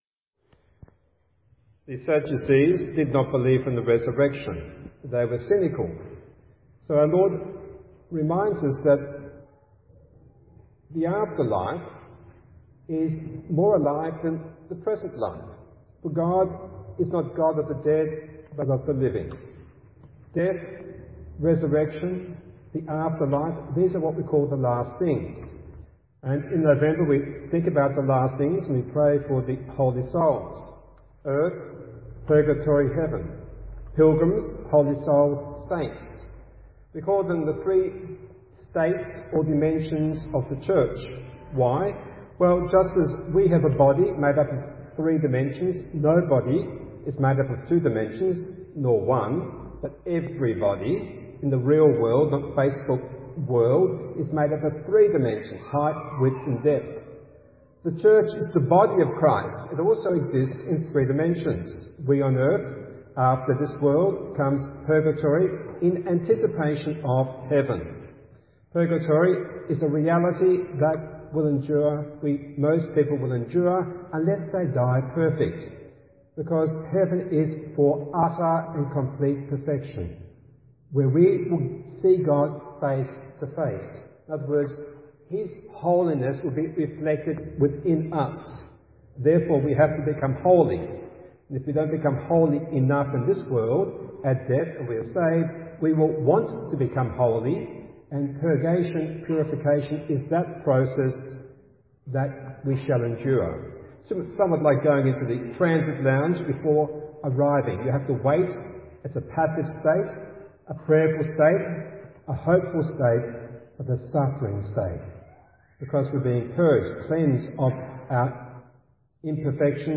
Recorded Homily, Sunday 6th November 2016: